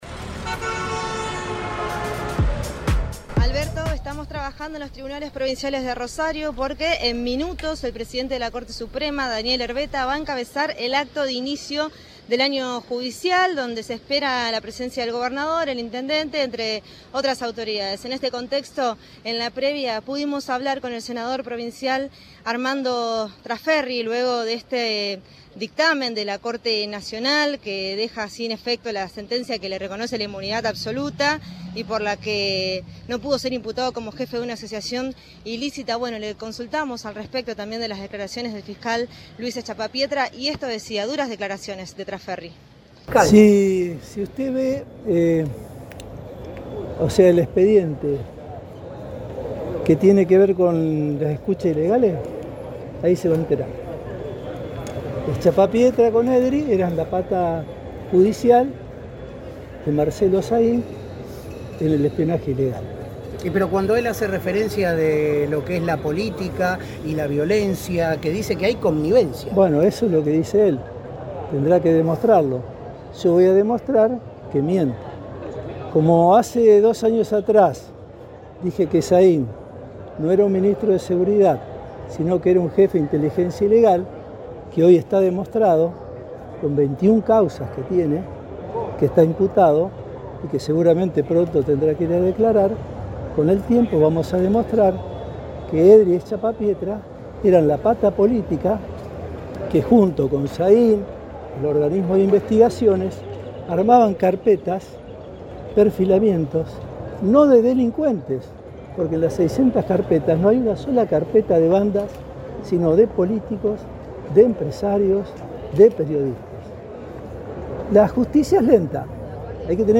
Armando Traferri, senador provincial peronista, dialogó con el móvil de Cadena 3 Rosario, en Siempre Juntos, y apuntó contra el fiscal Luis Schiappa Pietra, que volvió a acusarlo.